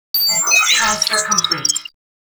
TransferComplete.wav